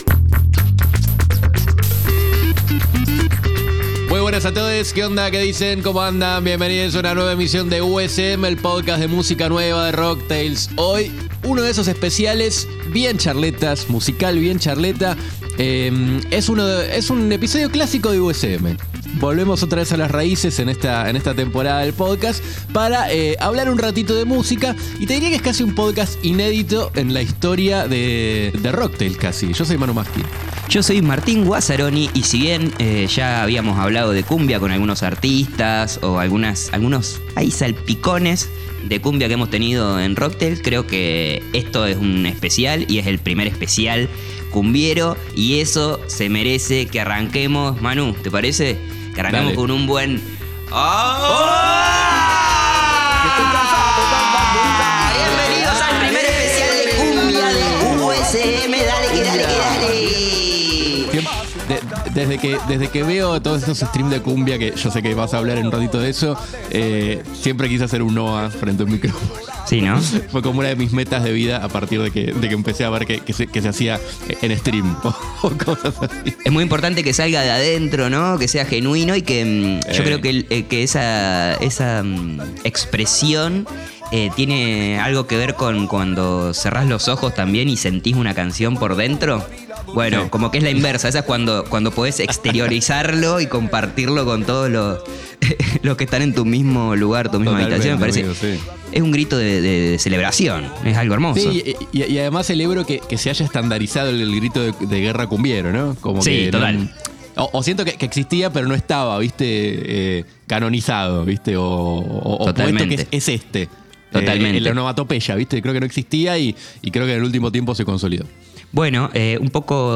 Este es un episodio clásico de USM, a pura charla musiquera, en donde trataremos de explicar, pensar y entender algunas de las causas de esta nueva ola del movimiento cultural cumbiero.